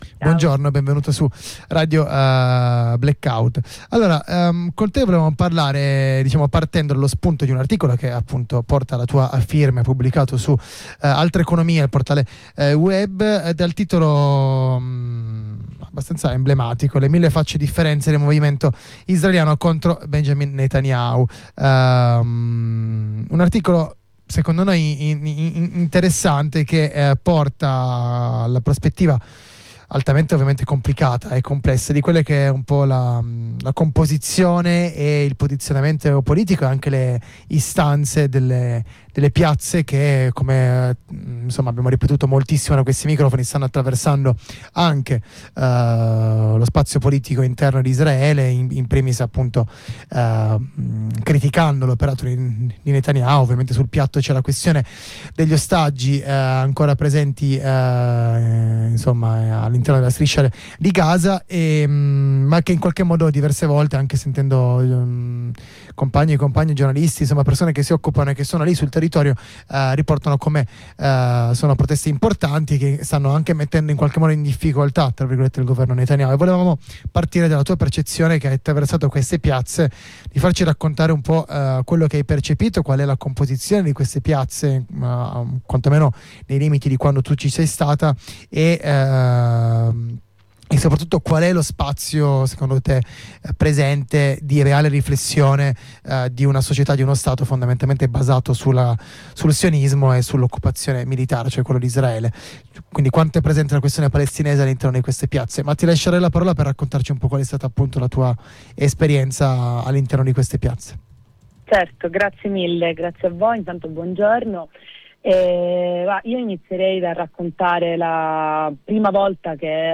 Ascolta la diretta su RadioBlackout: